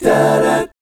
1-DMI7    -L.wav